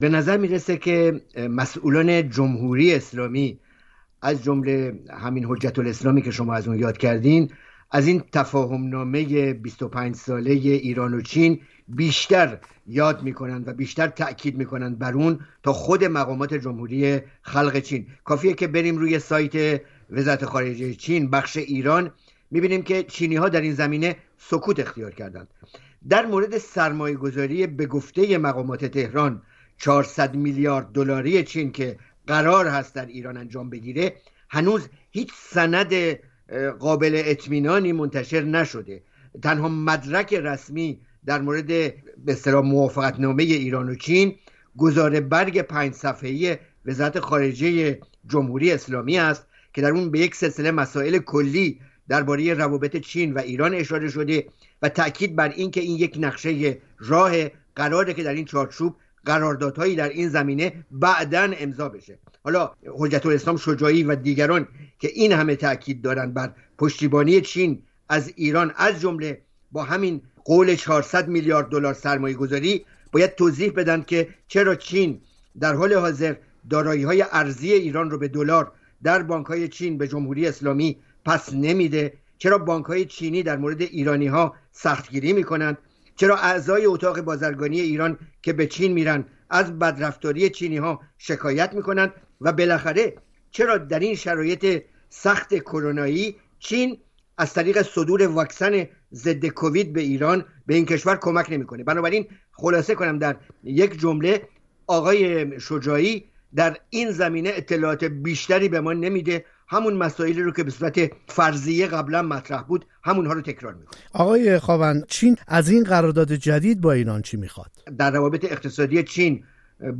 در گفت‌وگویی